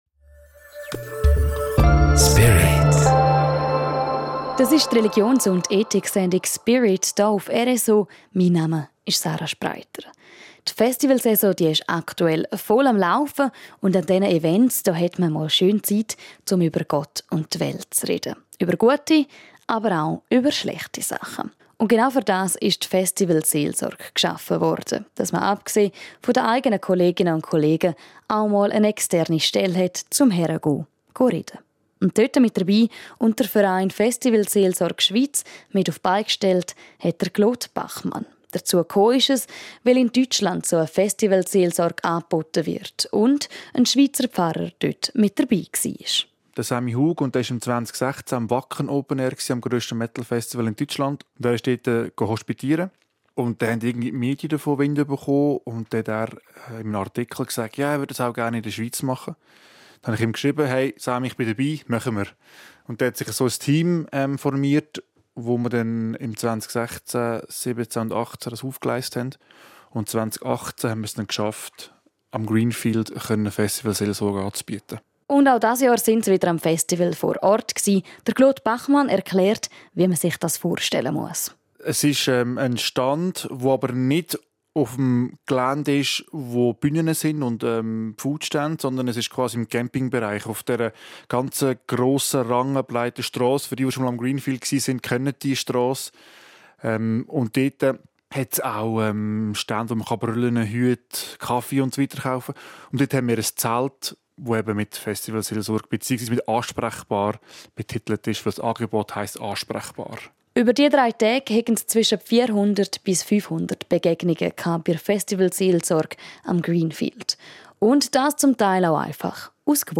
Wir sprechen mit ihm über das Angebot und die Zukunftspläne des Vereins.